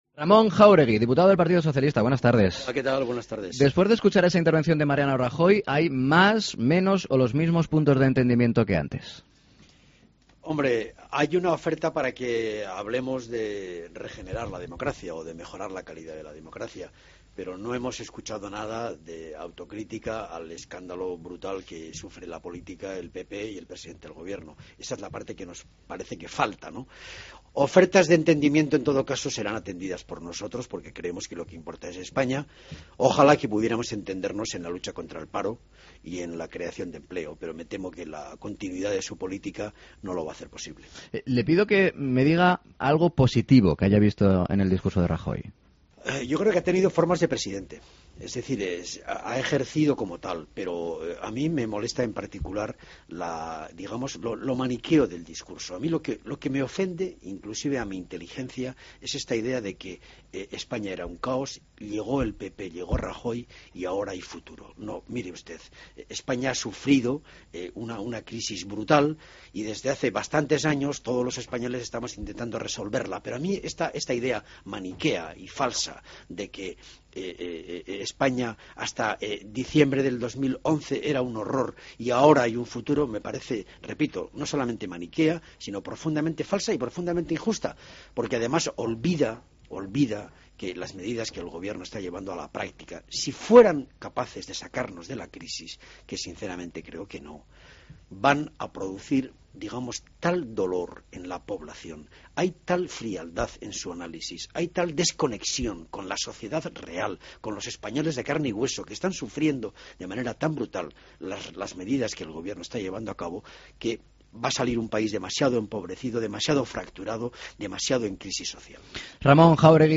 Escucha a Ramón Jáuregui, diputado PSOE en Mediodía COPE: No hemos escuchado autocritica al escandalo que sufre la política, el PP y el presidente